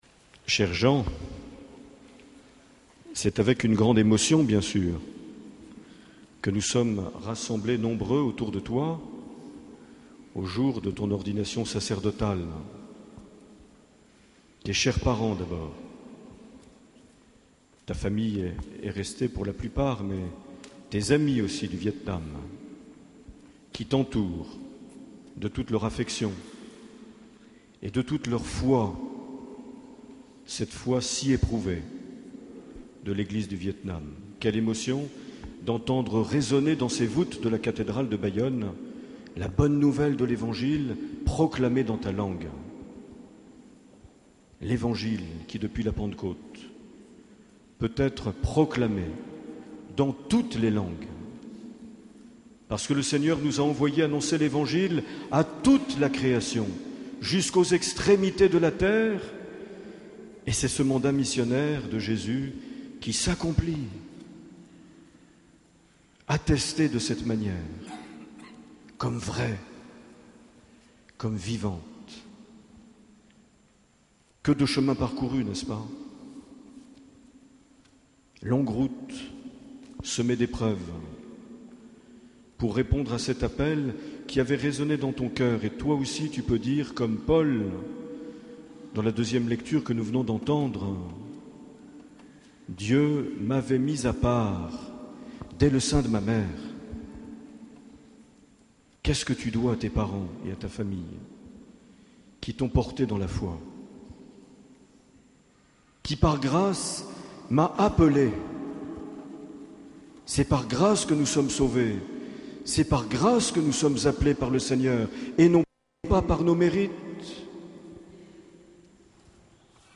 Accueil \ Emissions \ Vie de l’Eglise \ Evêque \ Les Homélies \ 28 juin 2009 - Bayonne Cathédrale Saint Marie - Ordination presbytérale de (...)
Une émission présentée par Monseigneur Marc Aillet